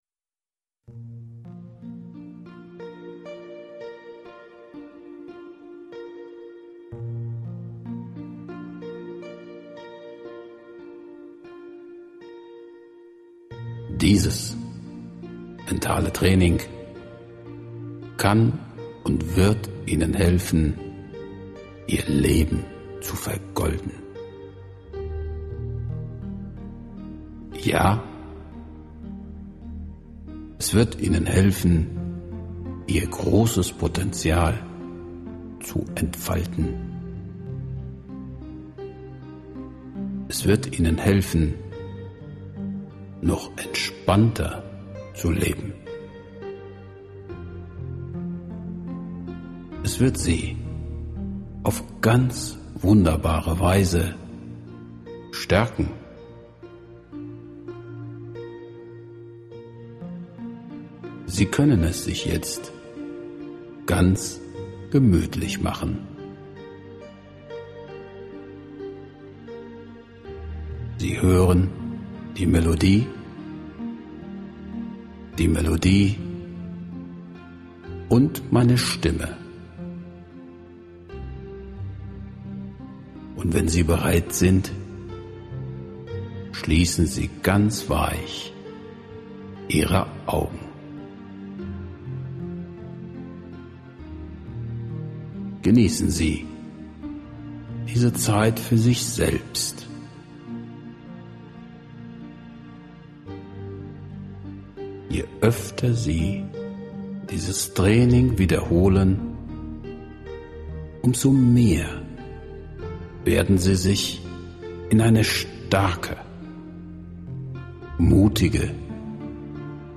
Willkommen zu Ihrem persönlichen H E M M U N G S L O S – Mentaltraining!